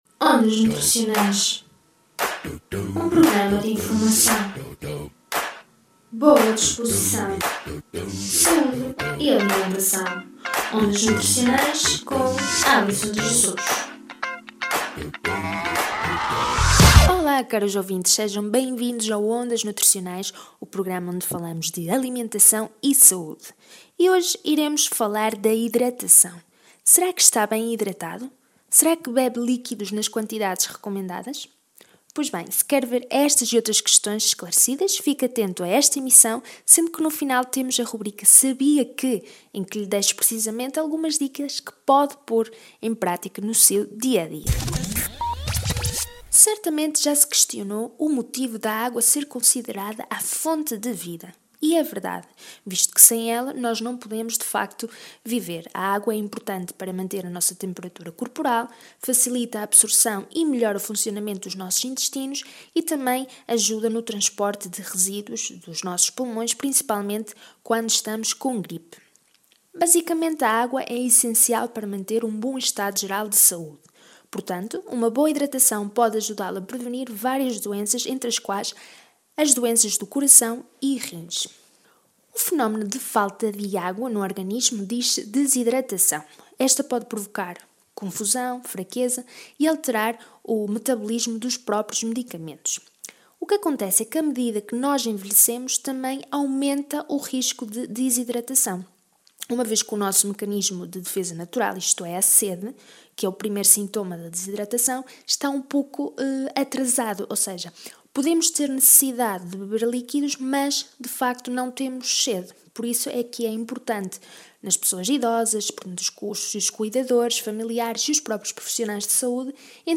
Os programas de rádio que aqui se apresentam foram realizados no âmbito da colaboração do Ondas Nutricionais com o Projeto Nutrition UP 65.